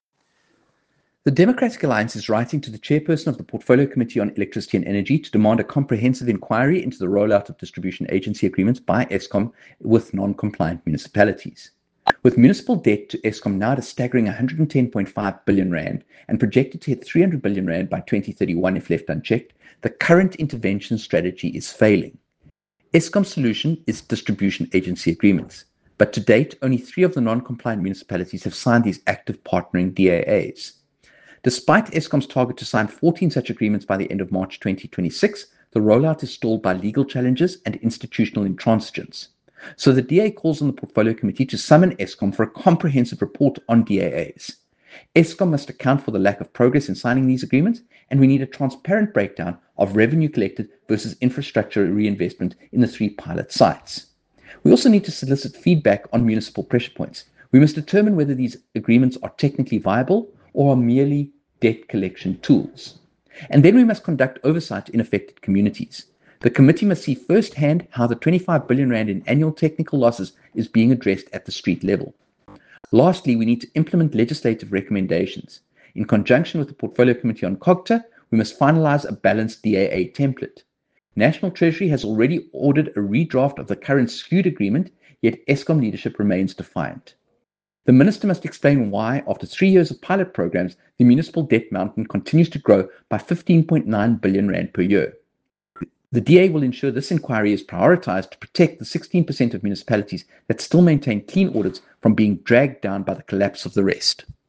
Soundbite by Kevin Mileham MP.